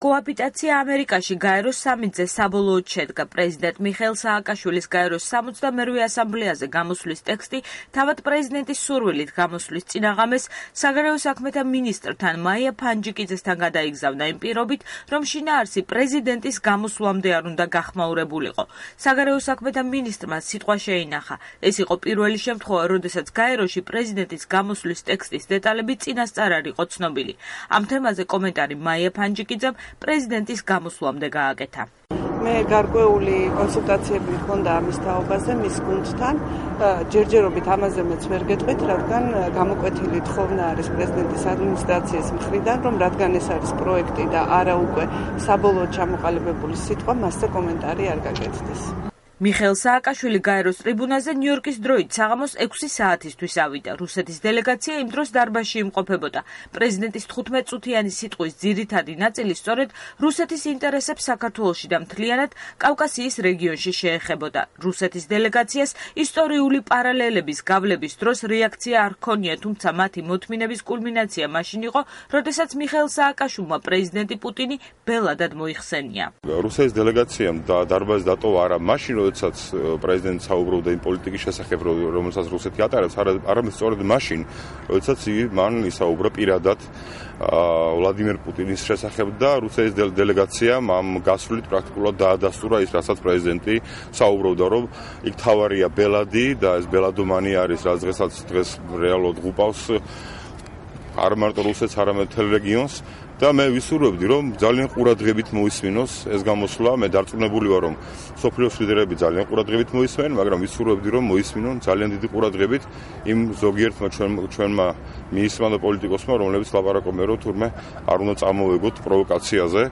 მიხეილ სააკაშვილის ბოლო გამოსვლა გაეროში